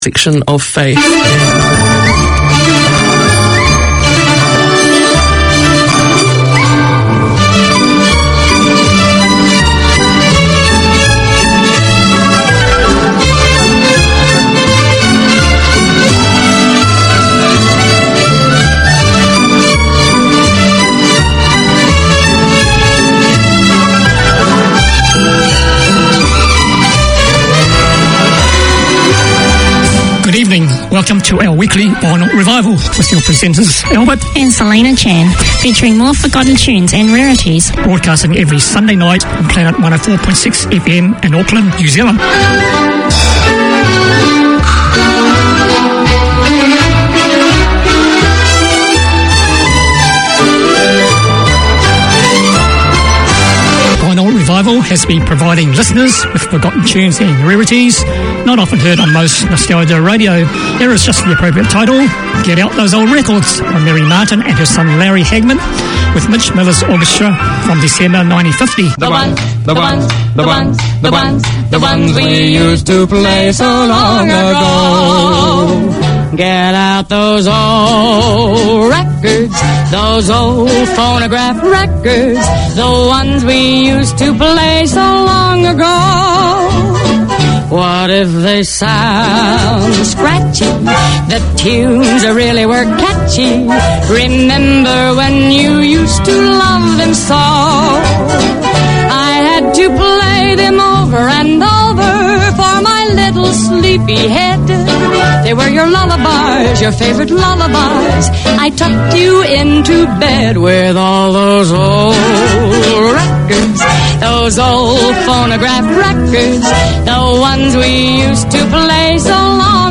Community Access Radio in your language - available for download five minutes after broadcast.
Garden Planet tackles everything from seasonal gardening and garden maintenance, to problem-solving, troubleshooting, and what to plant and when. Tune in for garden goss, community notices and interviews with experts and enthusiasts on all things green or growing.